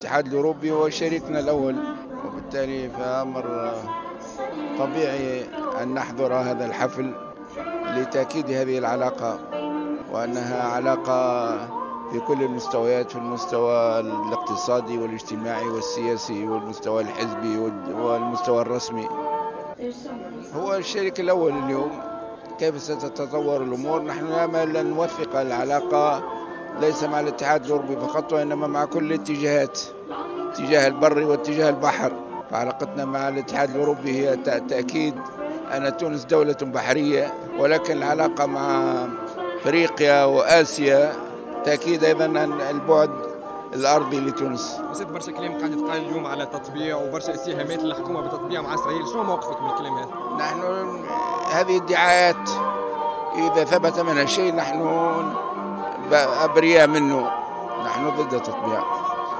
قال رئيس حركة النهضة راشد الغنوشي اليوم 14 جوان 2019 خلال حضوره فعاليات النشاط الديبلوماسي للاتحاد الأوروبي بالعاصمة وبخصوص سؤاله عن الاتهامات للحكومة بالتطبيع مع إسرائيل أن كل ذلك مجرد ادعاءات لاقتا بقوله'' حتى لو ثبت شيء من ذلك فالنهضة بريئة منه لأننا ضد التطبيع ''
وأكد الغنوشي في تصريحه لراديو موزاييك أن الاتحاد الأوروبي يظل الشريك الأول لتونس وحضور هذا الحفل أمر طبيعي لتأكيد العلاقات المتينة السياسية والرسمية والاقتصادية والحزبية.